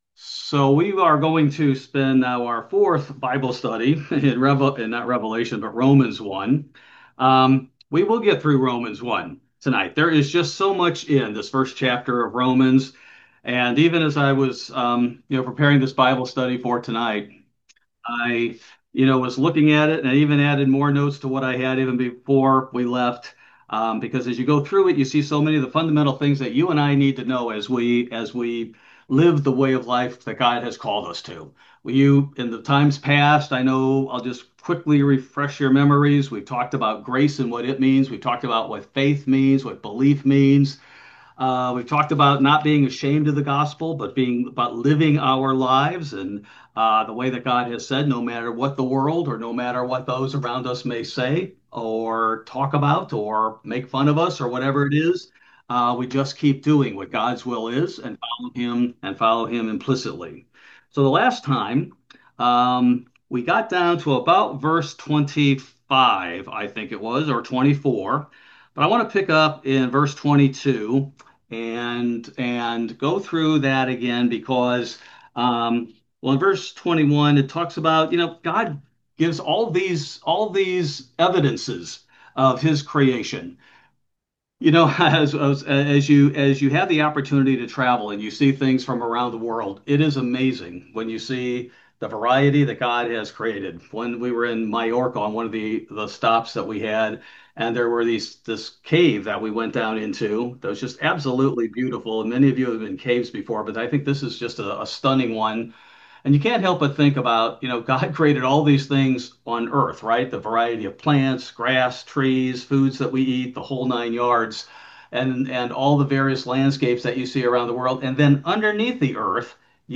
Bible Study: July 16, 2025